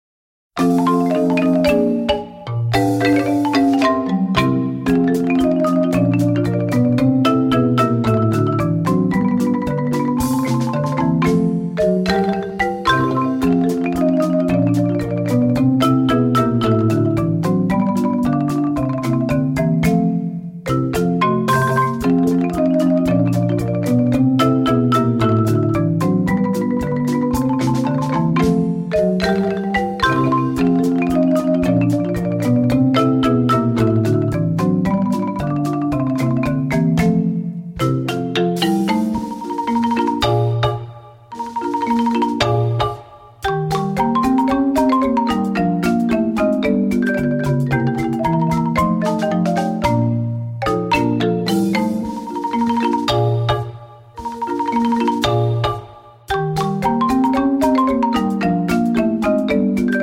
chamber percussion group